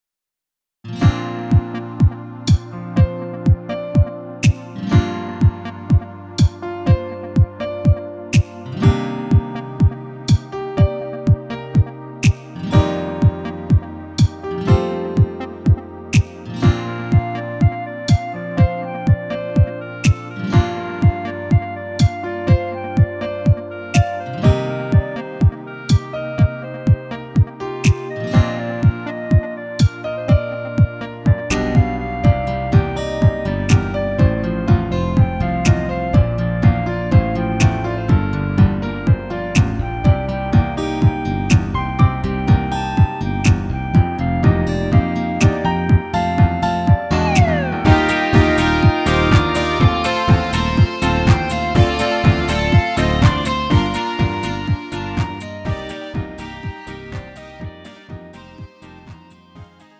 음정 -1키 3:17
장르 가요 구분 Lite MR
Lite MR은 저렴한 가격에 간단한 연습이나 취미용으로 활용할 수 있는 가벼운 반주입니다.